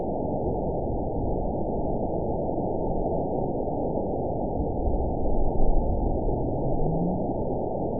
event 912515 date 03/28/22 time 14:13:40 GMT (3 years, 1 month ago) score 9.64 location TSS-AB04 detected by nrw target species NRW annotations +NRW Spectrogram: Frequency (kHz) vs. Time (s) audio not available .wav